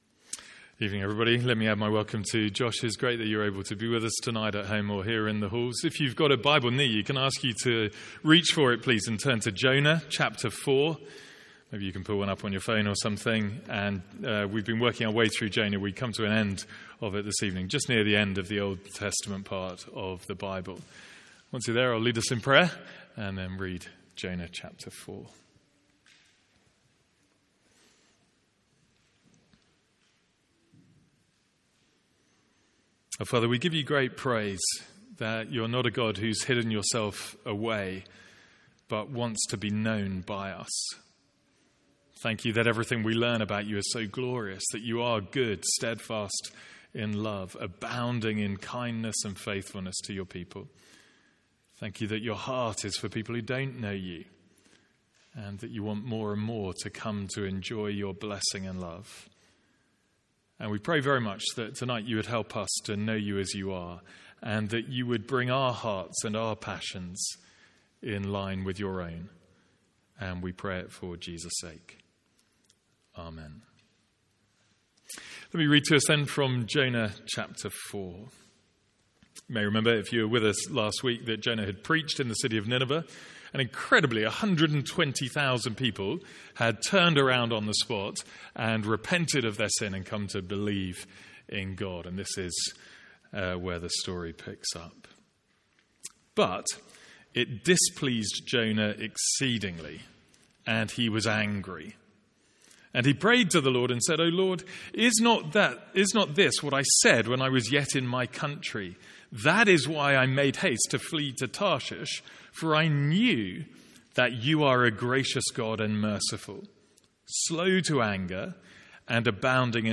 Sermons | St Andrews Free Church
From our evening series in Jonah.